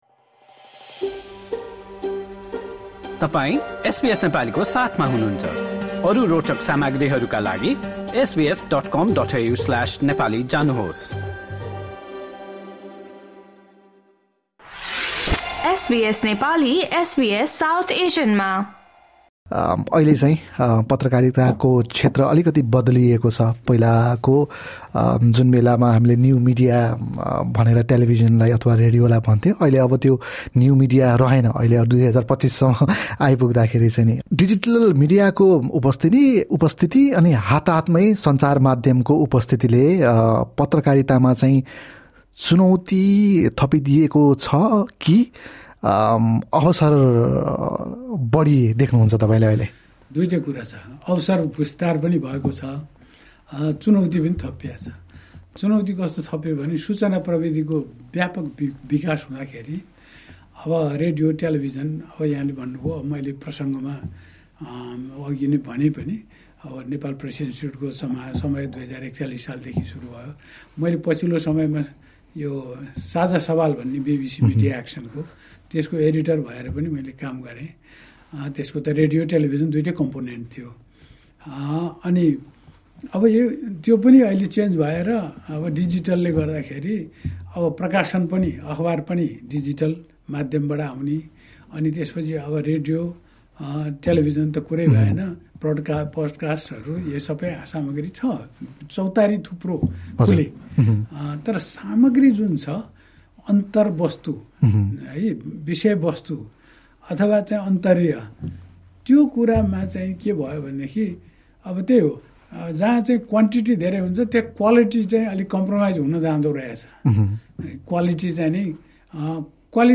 सिड्नी स्थित् आटामनमा रहेको एसबीएसको स्टुडियो आएका बेला उनले रेडियो, टेलिभिजन र डिजिटल मिडियाको आगमनसँगै मिडियाका अवसर र चुनौतीहरूका बारेमा एसबीएस नेपालीसँग चर्चा गरेका थिए।